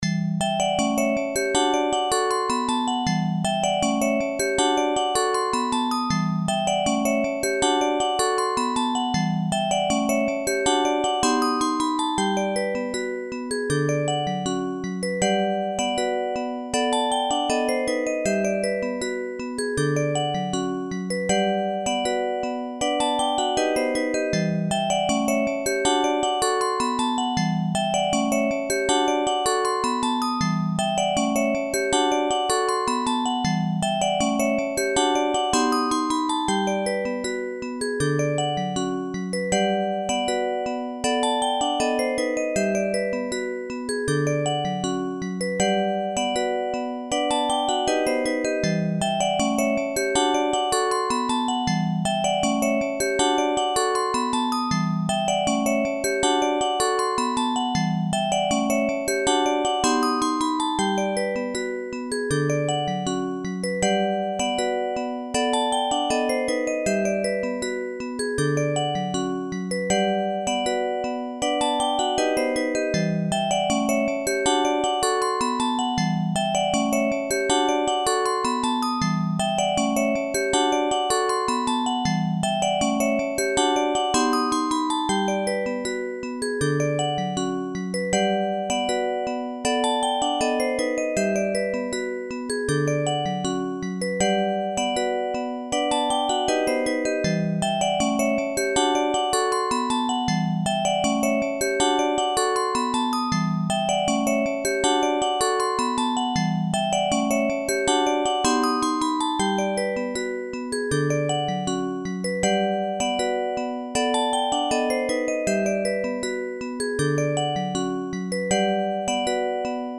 LOOP推奨： LOOP推奨
楽曲の曲調： SOFT
高さと速さが変わっています。